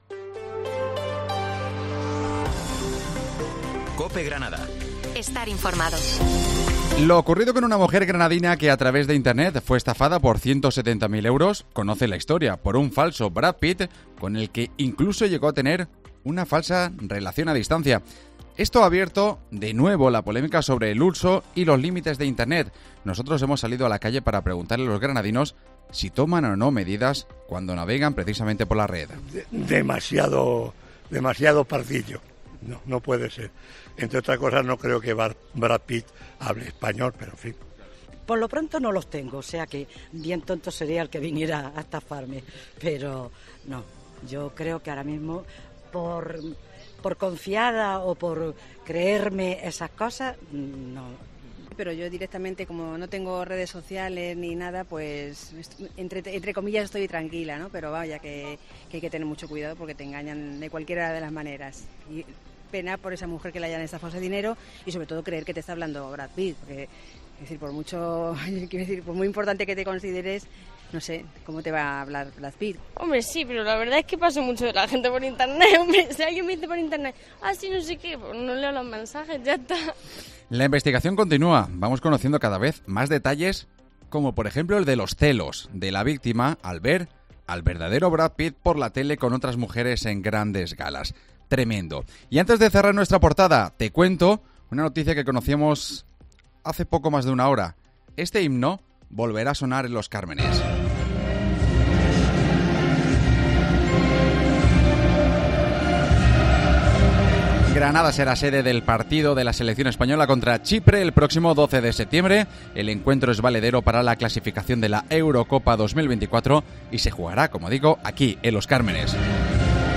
Informativo Mediodía Granada - 30 Junio